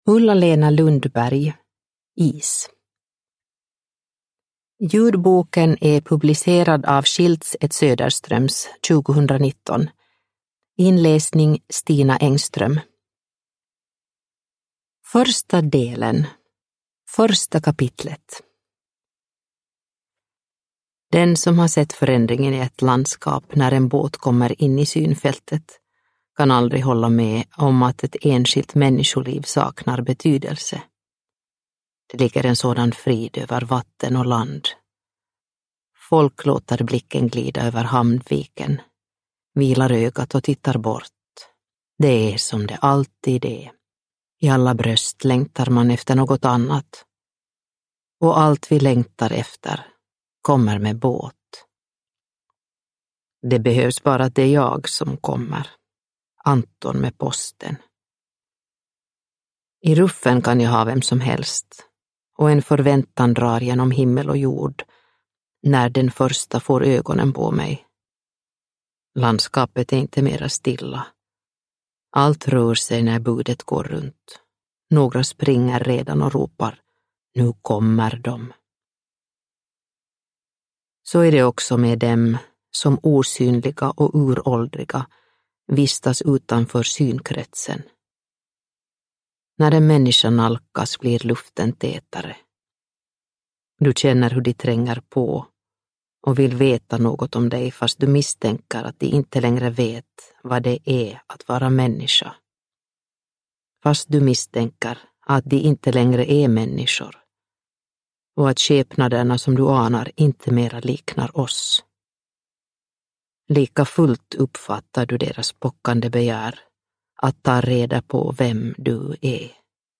Is – Ljudbok – Laddas ner